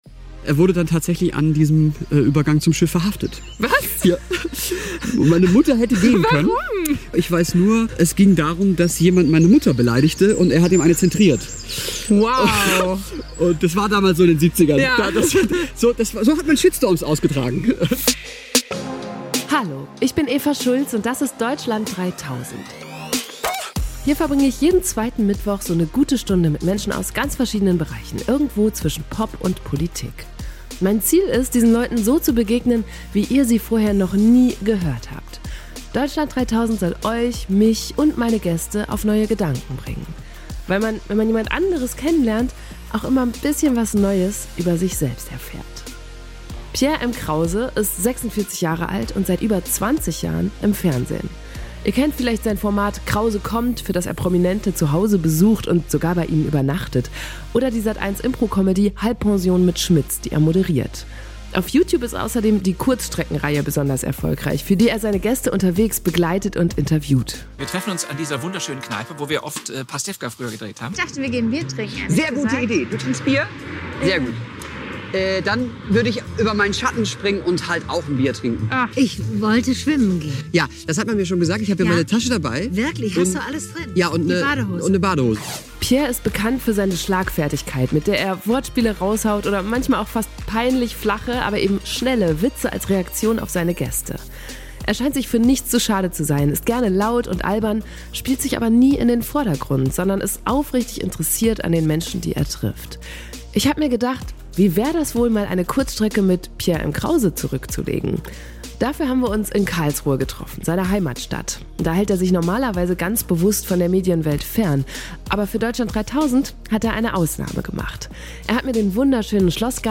Ich habe mir gedacht, wie wäre es wohl, mal eine Kurzstrecke mit Pierre M. Krause zurückzulegen? Dafür haben wir uns in Karlsruhe getroffen, seine Heimatstadt.
Er hat mir den wunderschönen Schlossgarten gezeigt und dabei von seiner Jugend erzählt, in der er offenbar ziemlich uncool war.